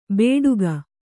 ♪ bēḍuga